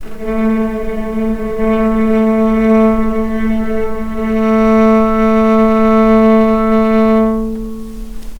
healing-soundscapes/Sound Banks/HSS_OP_Pack/Strings/cello/ord/vc-A3-pp.AIF at 2ed05ee04e9b657e142e89e46e1b34c4bb45e5a5
vc-A3-pp.AIF